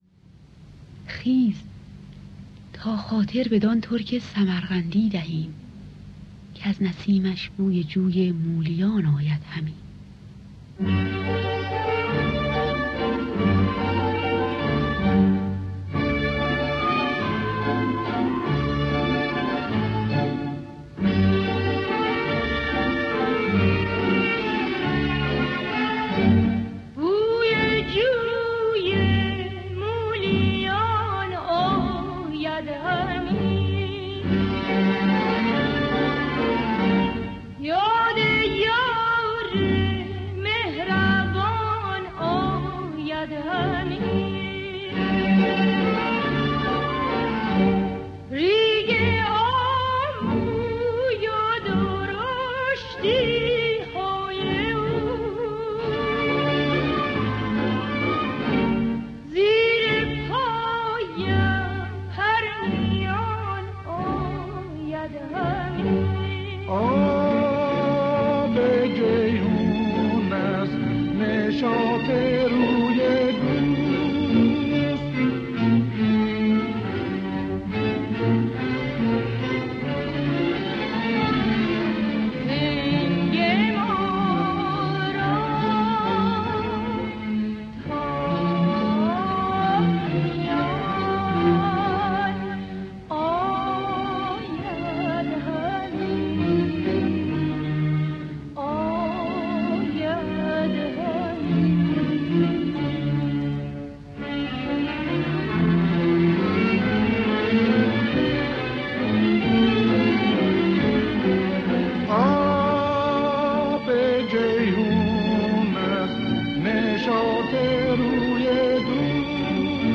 با صدای بانوان